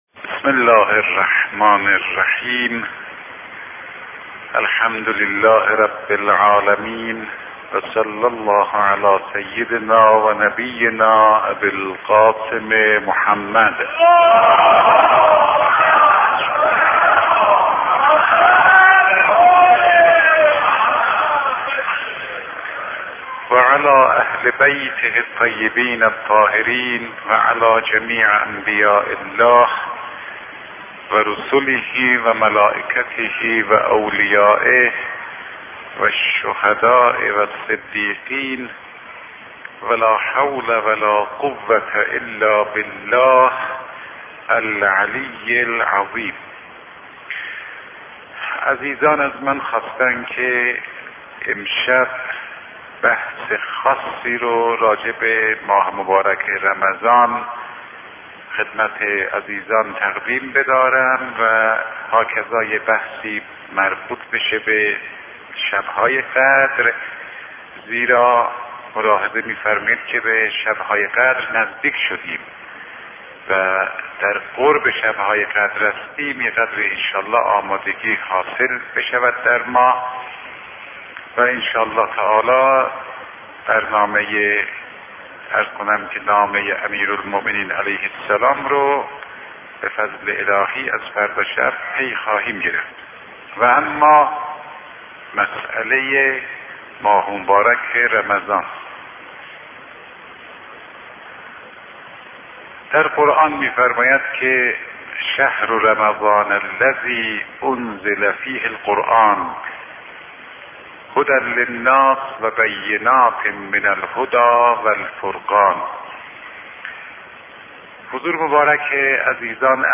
دانلود کنید در آستانه شبهای قدر متن یکی از سخنرانی های استاد فاطمی‌نیا درباره این شب جلیل القدر را در زیر بخوانید: رمضان سوزاننده